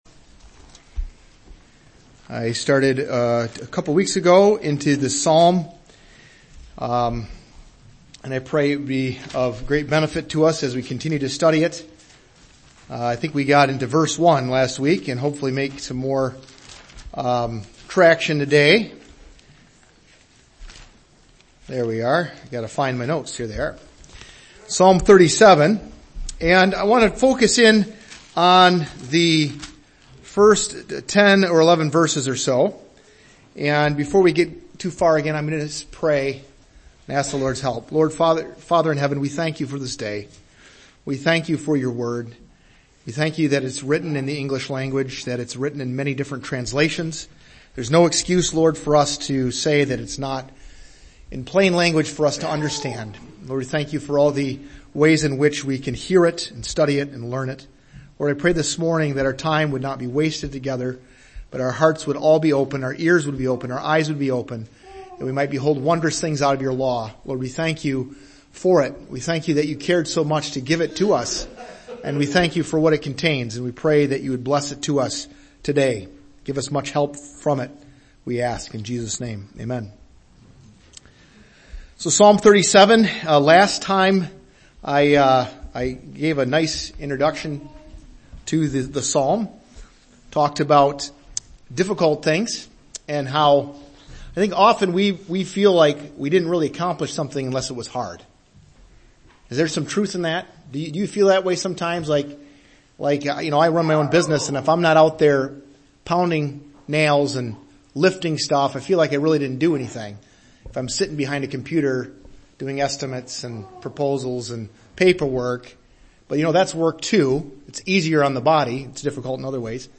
Note: These messages have been digitally edited to improve the listening experience.